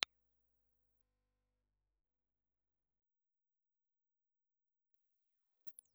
Piezoelectric Crystal
Cardioid
Speech (male) recorded with an Astatic D-104 crystal microphone.
Speech range frequency response from 500 to 4,000 cycles.